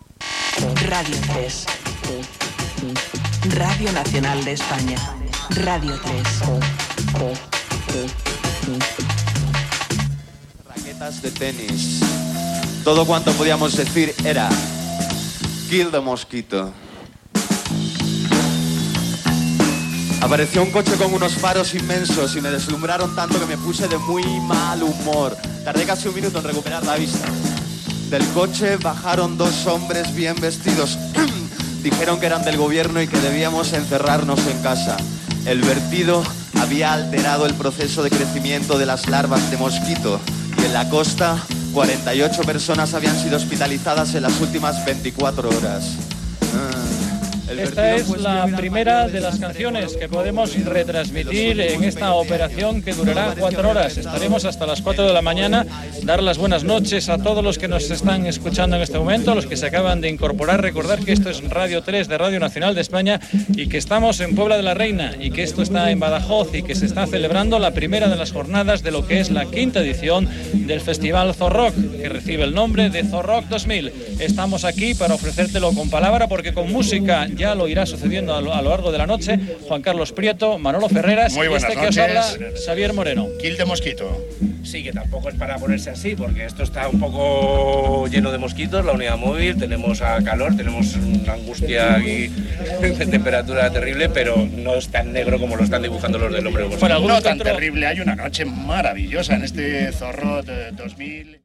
bb45b2bdc835407bb9b1efe197cfdfb7b87e4c5a.mp3 Títol Radio 3 Emissora Radio 3 Cadena RNE Titularitat Pública estatal Descripció Indicatiu de l'emissora i transmissió del V Festival Zorrock des de Puente de la Reina (Badajoz).